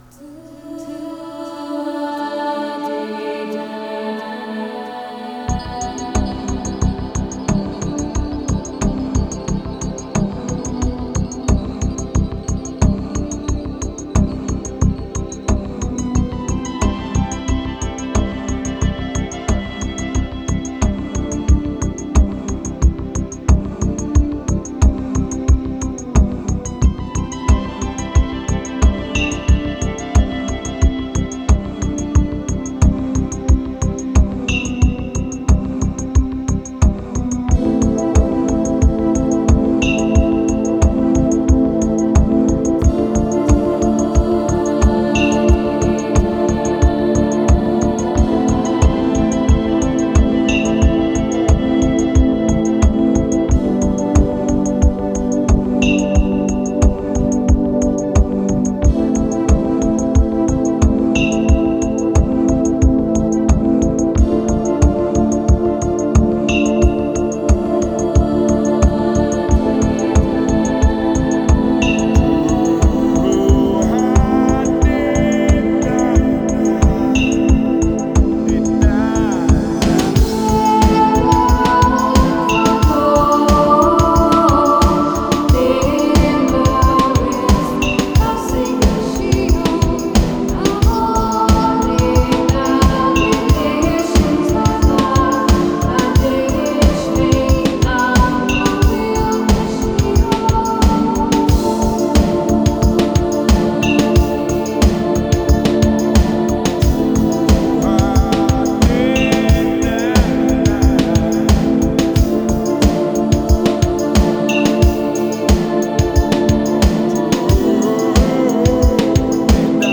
New age Музыка new age Нью эйдж